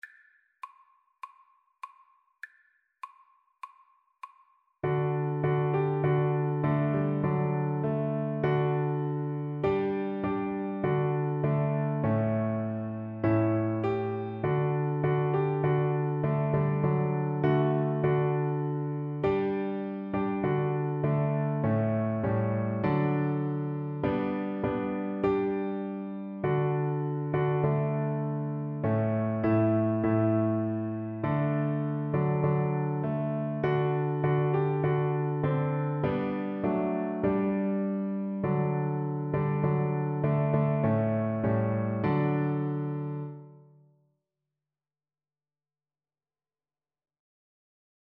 Christmas Christmas Cello Sheet Music Up on the House-Top
Cello
4/4 (View more 4/4 Music)
D major (Sounding Pitch) (View more D major Music for Cello )
Moderato
Classical (View more Classical Cello Music)